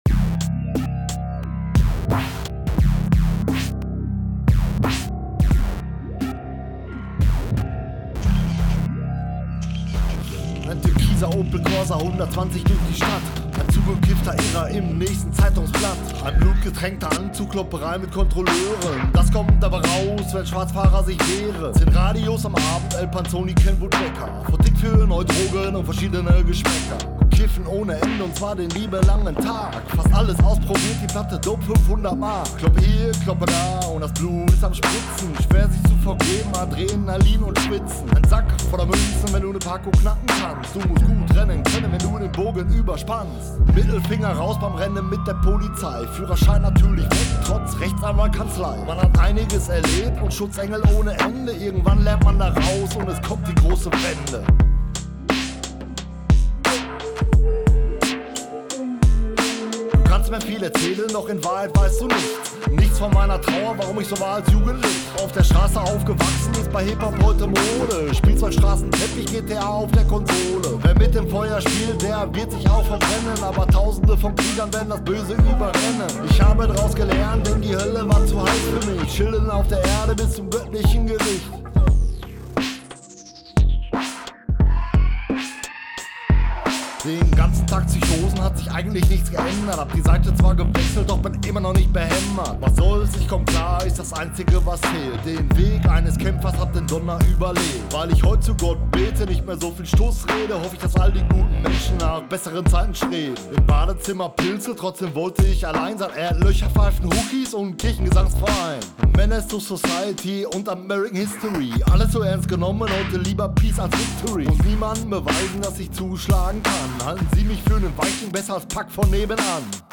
edit drums auf mono hilft XD
Vocals sind viel zu leise… die müssen ganz nach vorne.